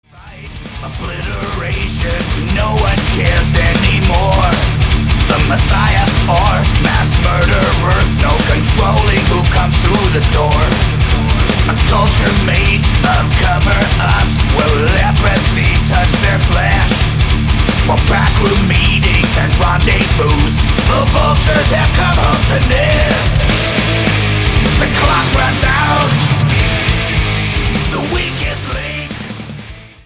Studio: Lattitude South Studios, Leiper's Fork, Tennessee
Genre: Thrash Metal, Heavy Metal